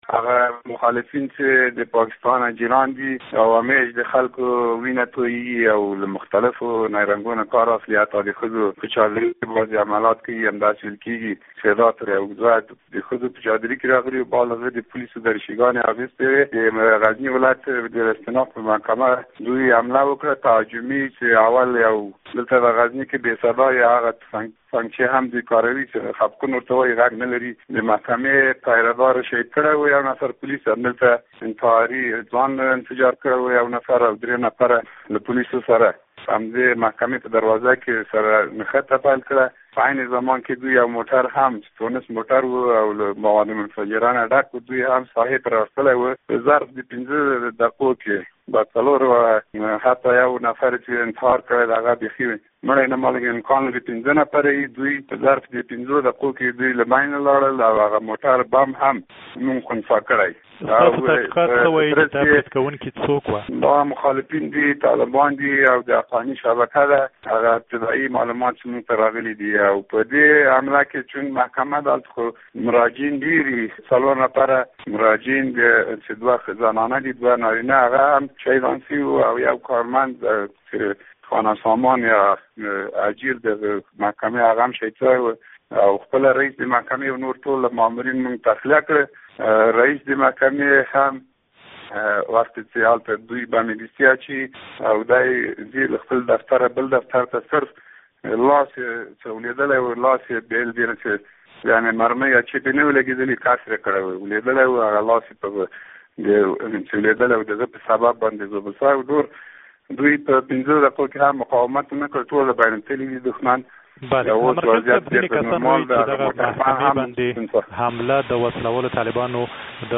مرکه
له جنرال امین الله امرخېل سره مرکه